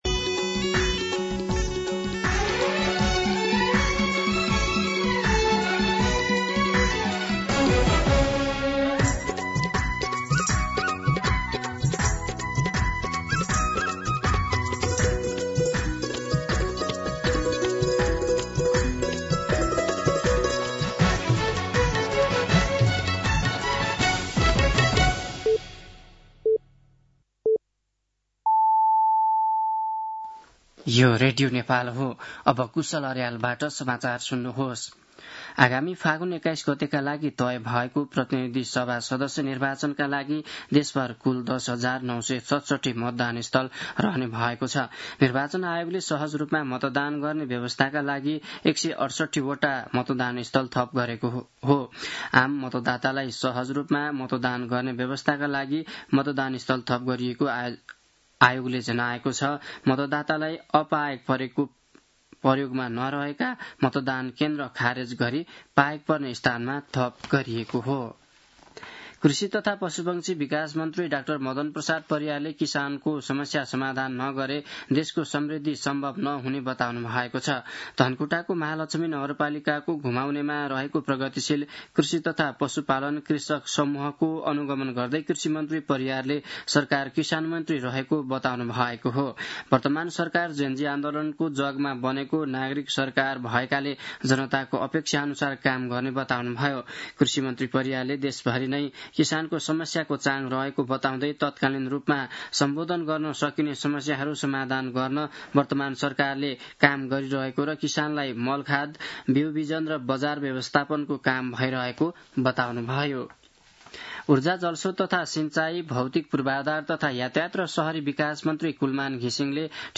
दिउँसो १ बजेको नेपाली समाचार : ५ पुष , २०८२